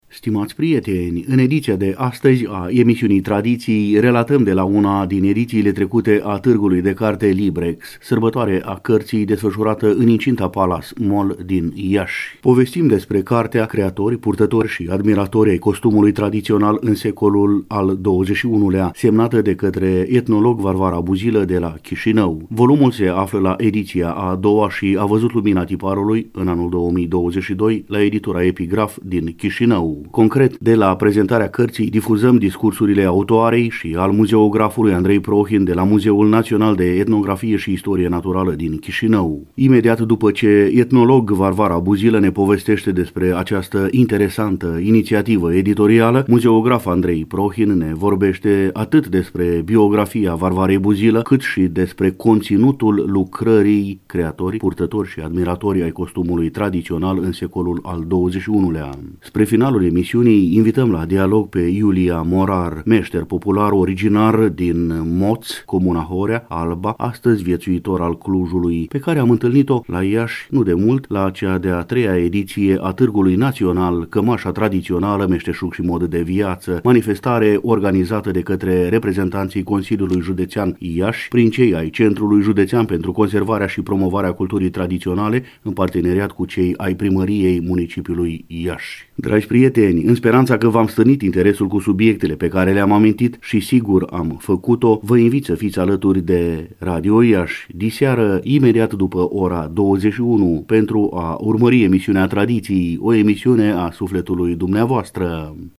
În ediția de astăzi a emisiunii Tradiții, relatăm de la una din edițiile trecute a Targului de Carte LIBREX, sărbătoare a cărții desfășurată în incinta Palas Mall din Iași.